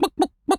Animal_Impersonations
chicken_cluck_bwak_seq_01.wav